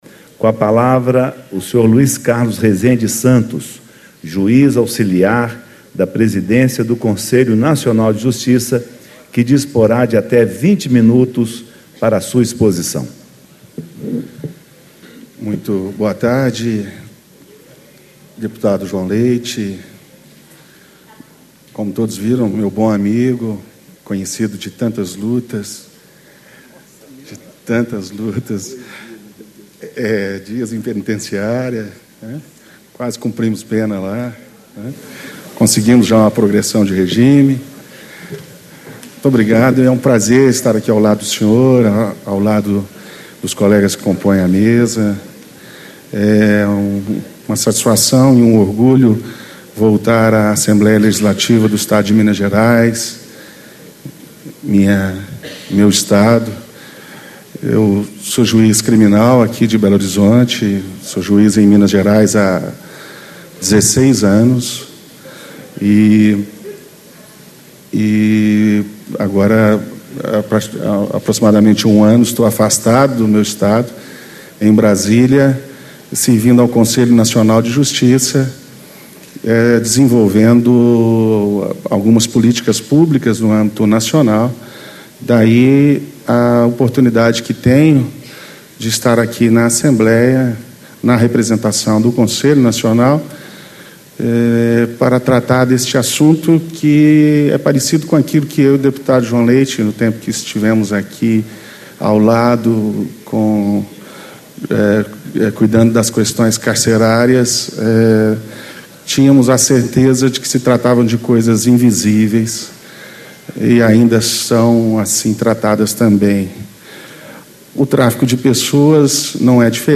Painel: Tráfico de pessoas e algumas de suas modalidades: exploração sexual, trabalho escravo e adoção ilegal - Luiz Carlos Rezende e Santos, Juiz auxiliar da Presidência do Conselho Nacional de Justiça
Discursos e Palestras